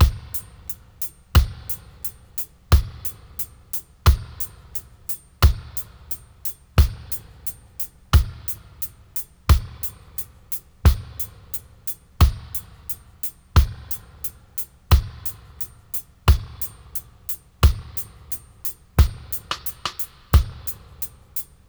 88-FX-03.wav